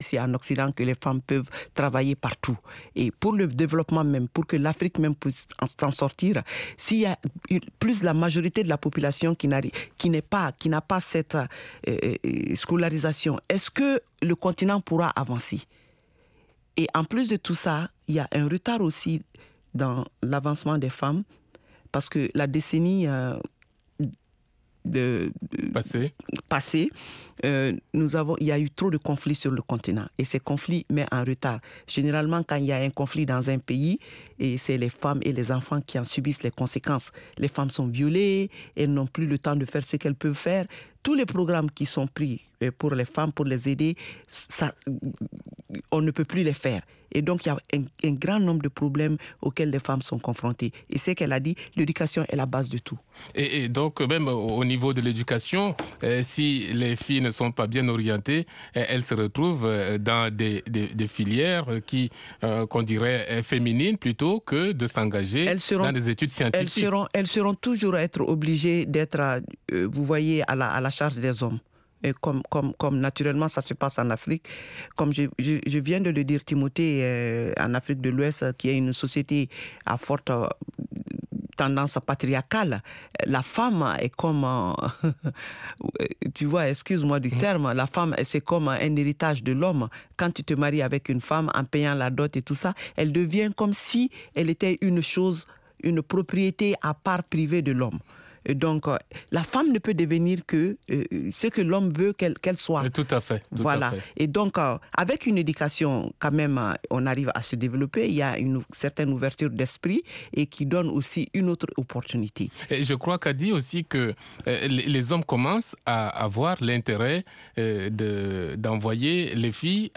LMF présente également des reportages exclusifs de nos correspondants sur le continent.